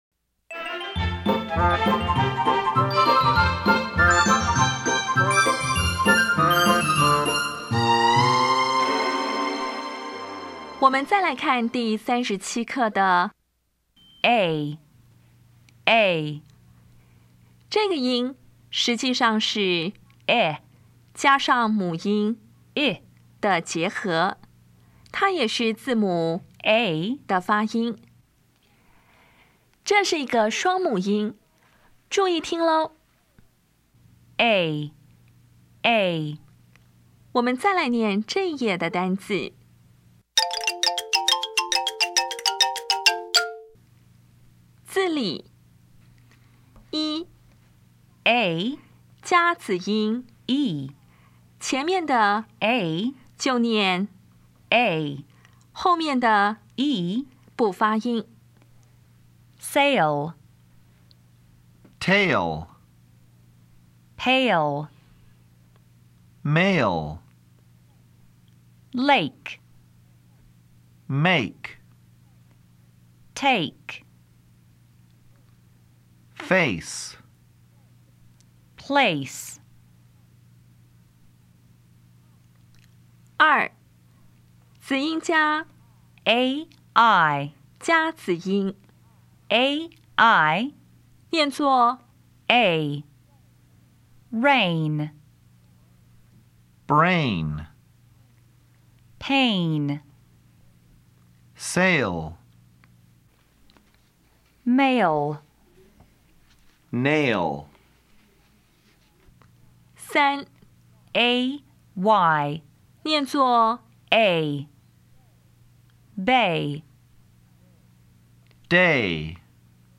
音标讲解第三十七课
3,  ay 念[e]
4,  ation念[e]
5, 子音ea子音  ea 念[e]